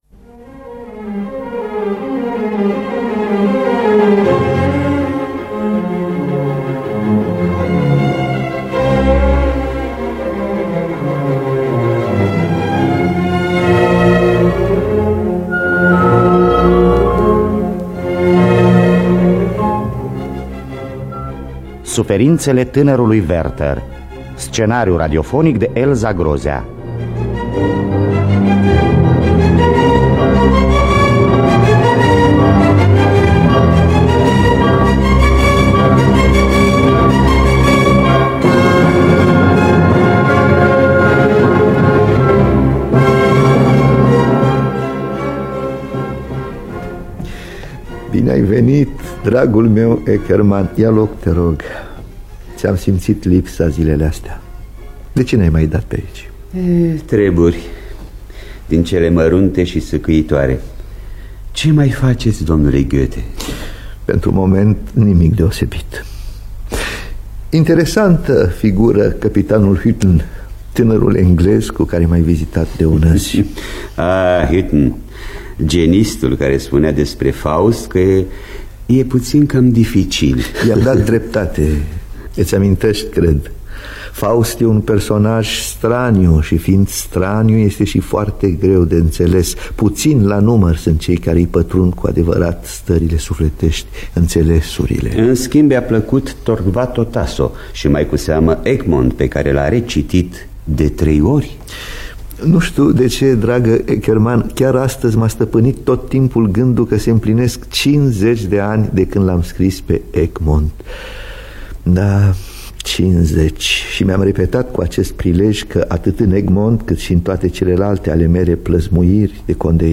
Biografii, Memorii: Johann Wolfgang von Goethe – Suferintele Tanarului Werther (1977) – Teatru Radiofonic Online